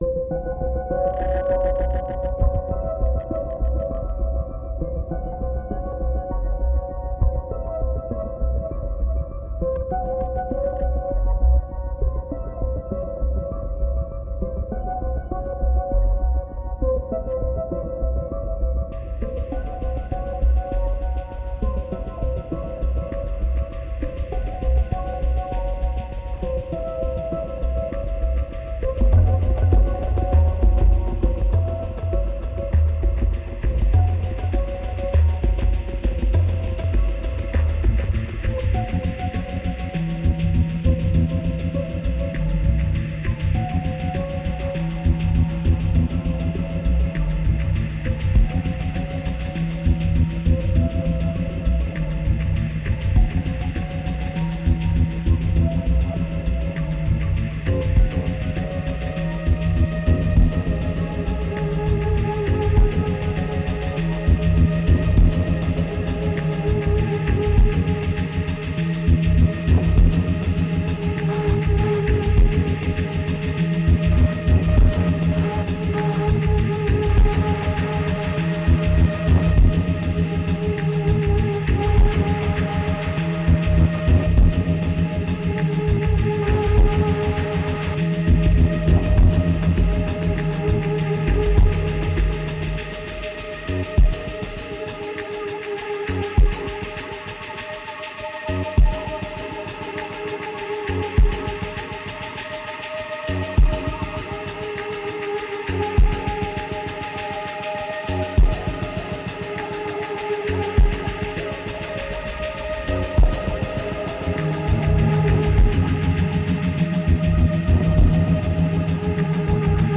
2133📈 - 36%🤔 - 100BPM🔊 - 2011-04-10📅 - -15🌟